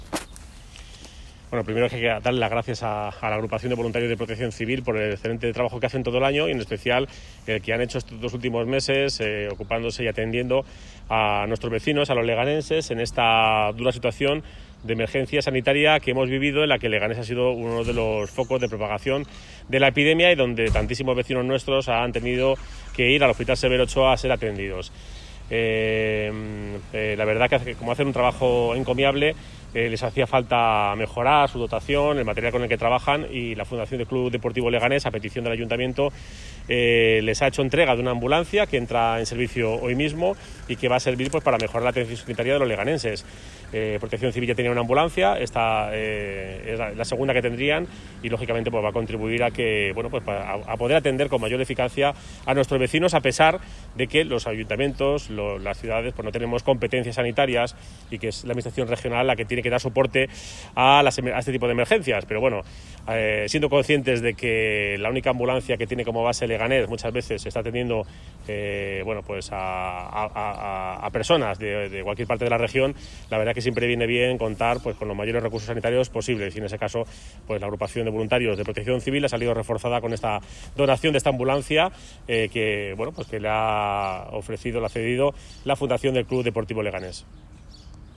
Santiago-Llorente-alcalde.mp3